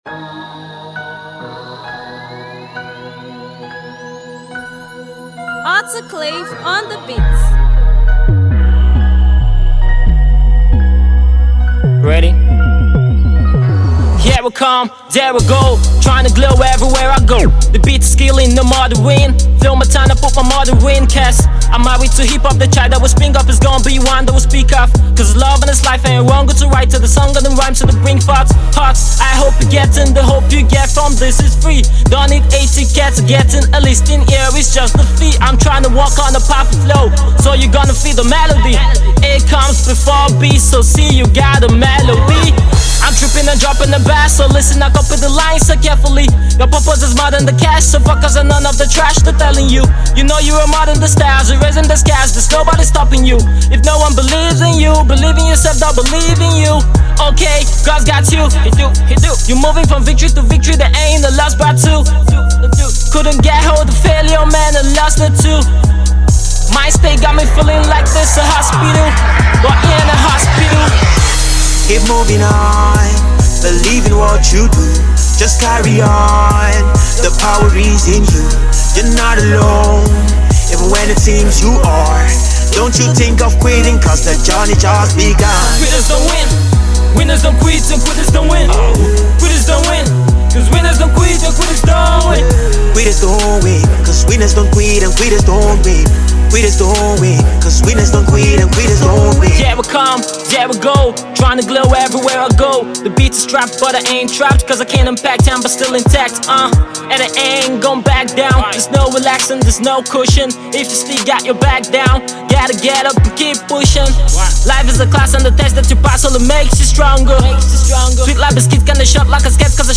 Gospel/inspirational rapper
motivating untrapped song on a trap beat